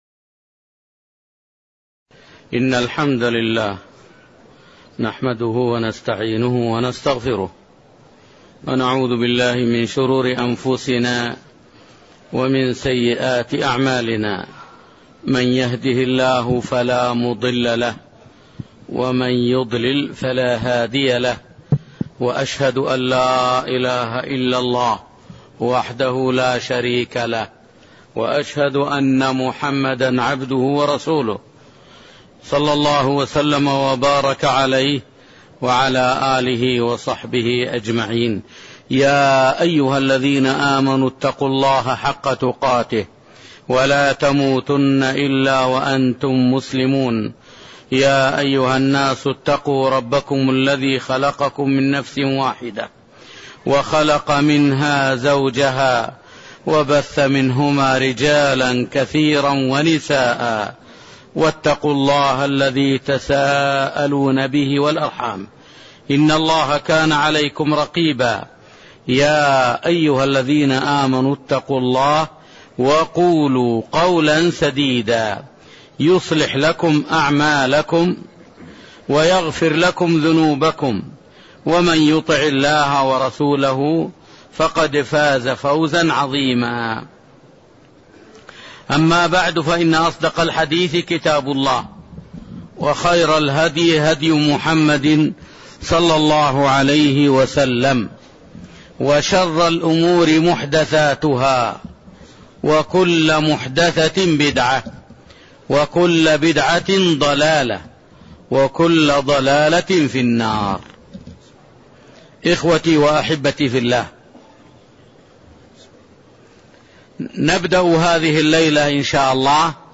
تاريخ النشر ١٩ ذو القعدة ١٤٣٠ هـ المكان: المسجد النبوي الشيخ